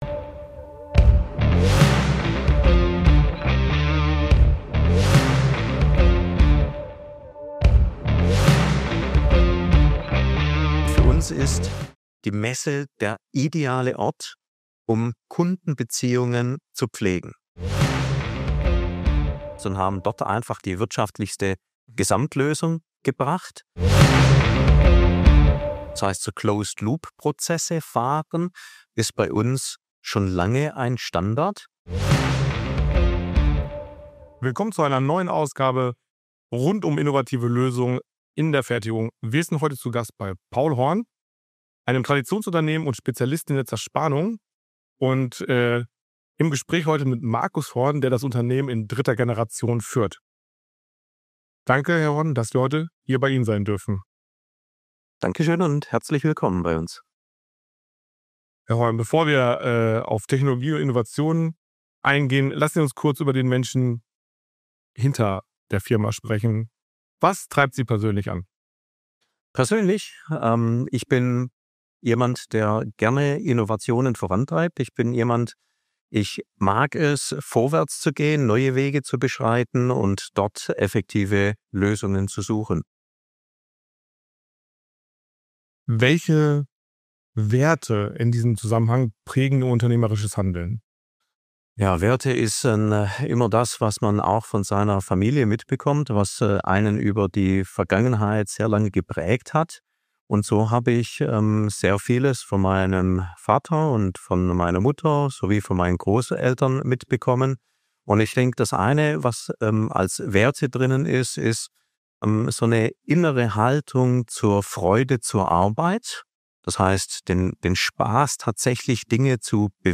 Diese und viele weitere spannende Fragen beantworten die Experten von Paul Horn in unserem exklusiven Gespräch.